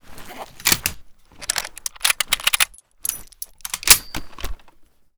sks_reload.ogg